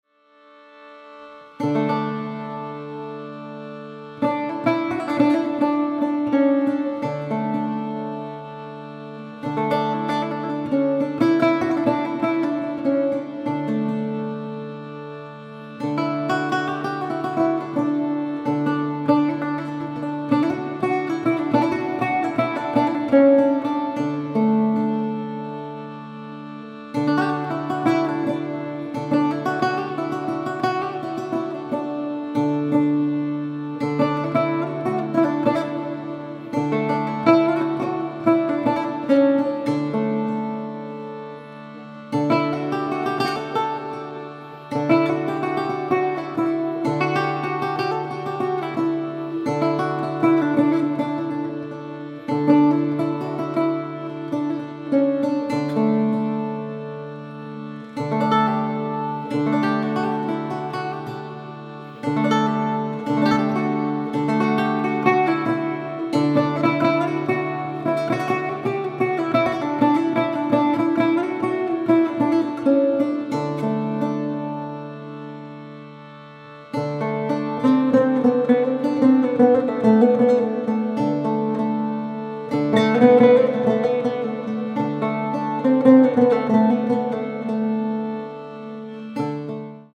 Contemporary
Lute